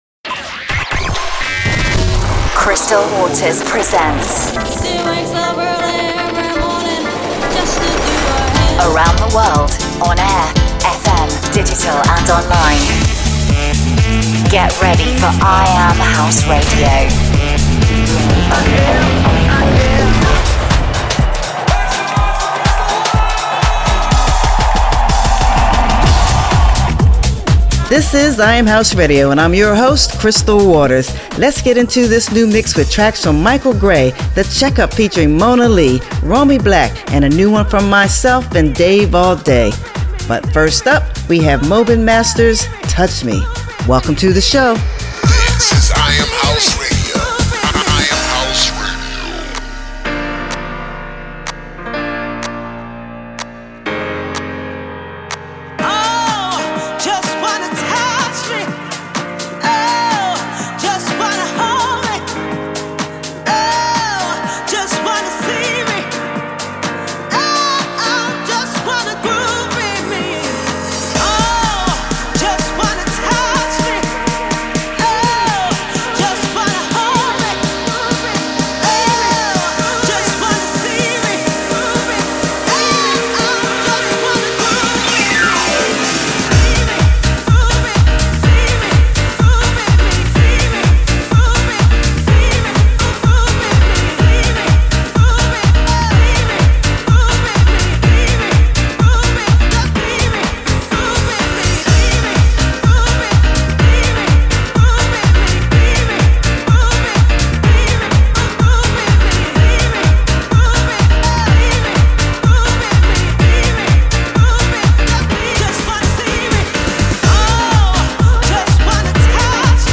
Playing the best new House Music from around the world.